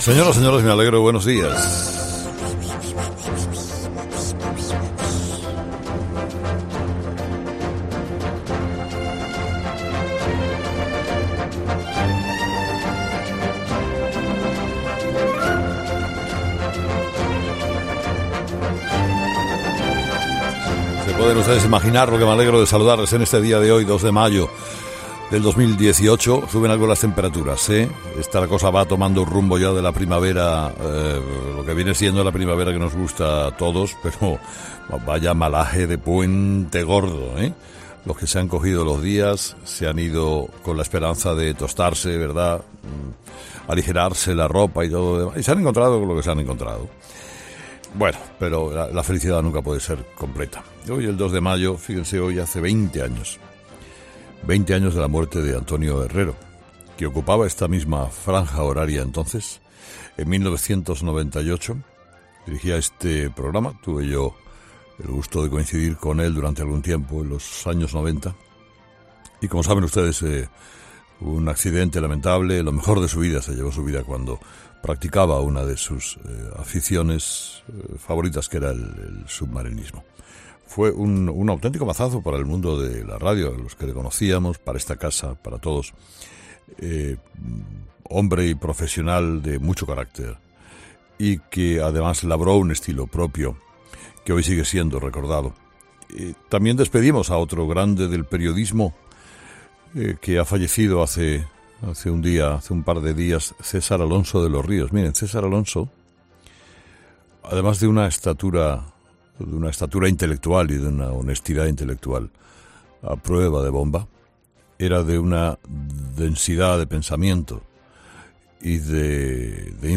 Monólogo de las 8 de Herrera
Con Carlos Herrera